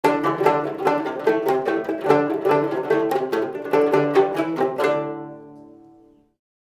A classy collection of Tibetan samples with a specially included movie.
For recording the genuine Tibetan samples on this library, I visited the refugee settlement under the Tibetan Government-in-Exile located in Dharamsala, Northern India.
Dranyen-B_Loop_010_bpm_146.2_D